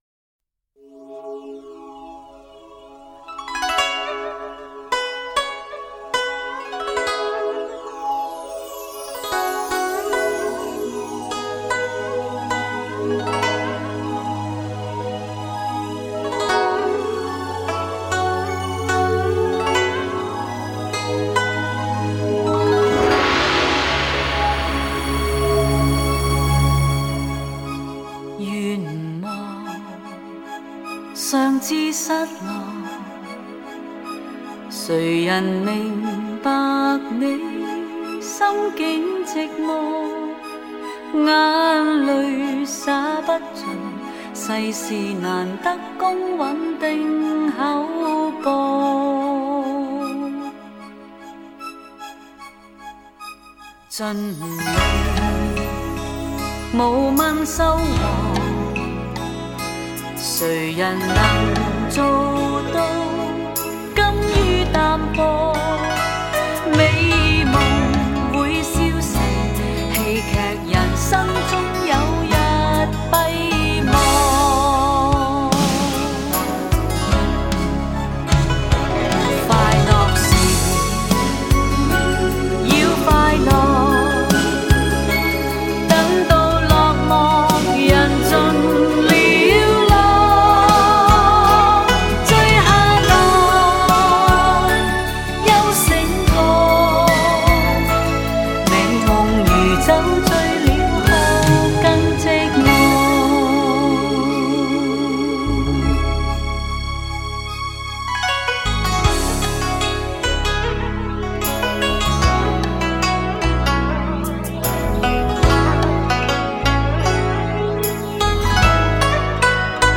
流泄而出的轻柔旋律 总是能抚慰每颗疲惫的心灵
高密度24BIT数码录音